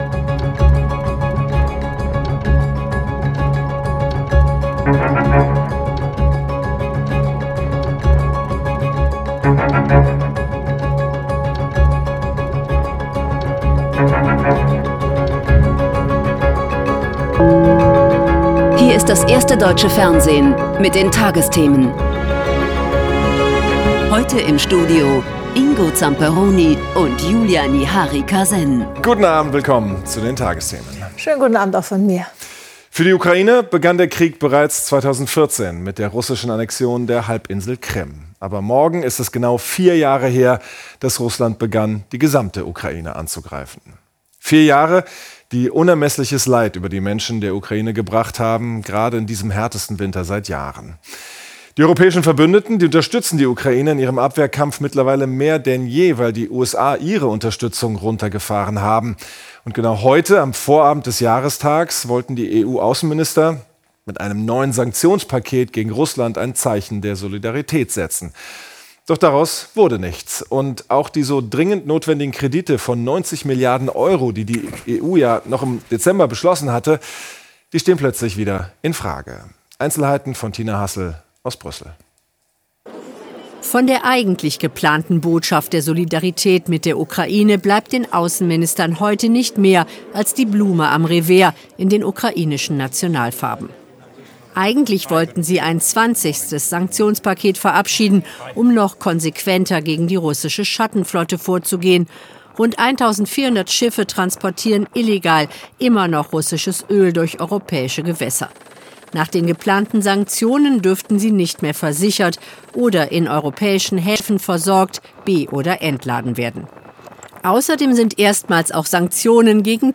Korrektur: Die Sendung wurde nachträglich bearbeitet.